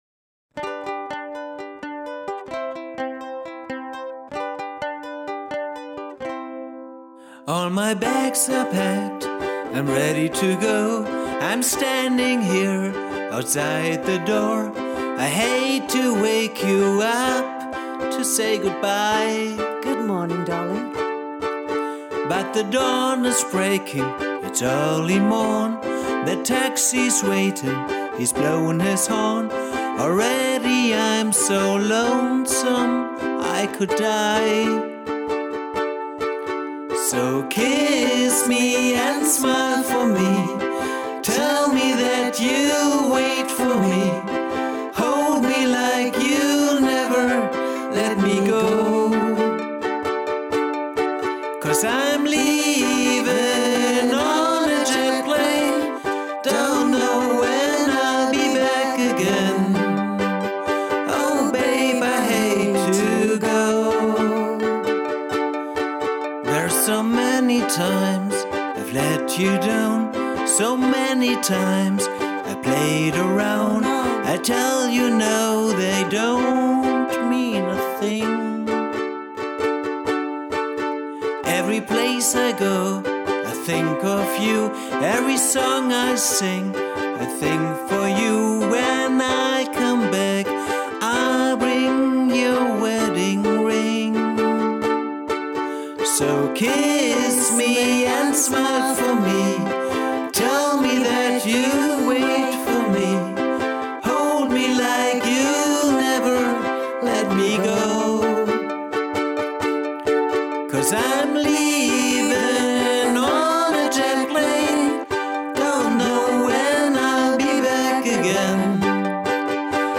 Ukulele Konzert
Strum-Along zum Mitspielen